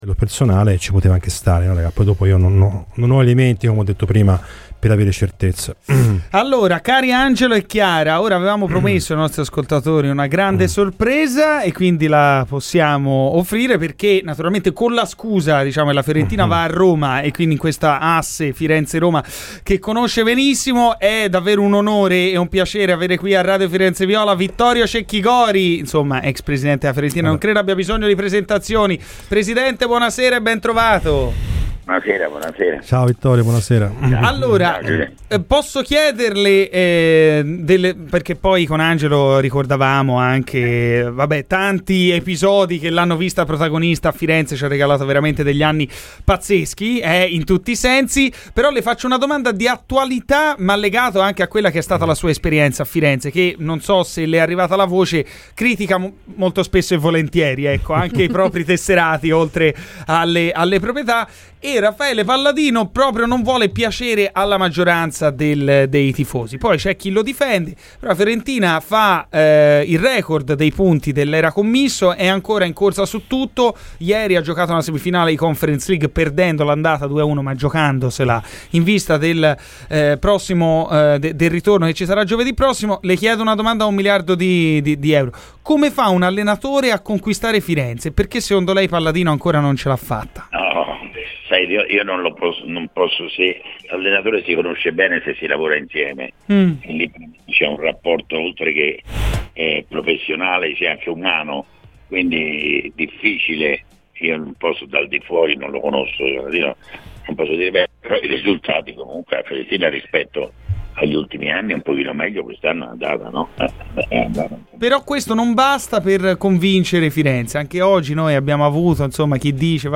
Intervenuto a Radio FirenzeViola nel corso di "Garrisca al Vento", queste sono state le sue dichiarazioni sulla Fiorentina: Palladino non ha conquistato tutti.